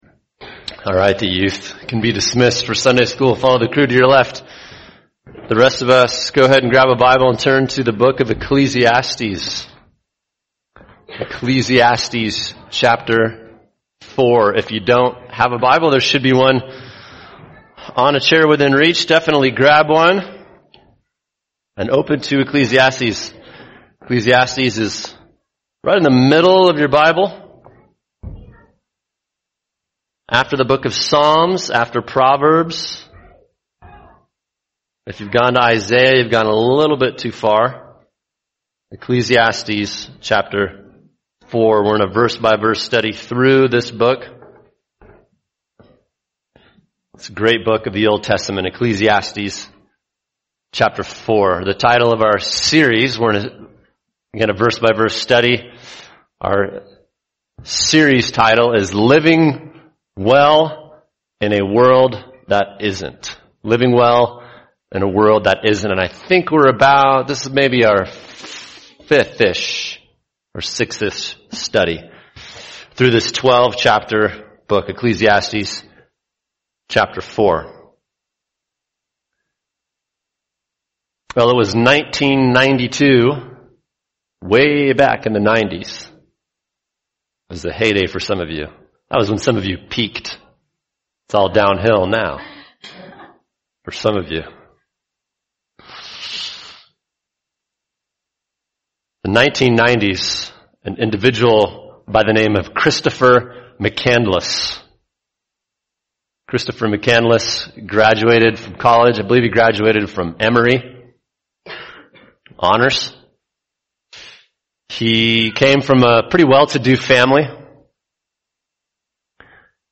[sermon] Ecclesiastes 4 Living Well In A World That Isn’t – The Need For One Another In A Broken World | Cornerstone Church - Jackson Hole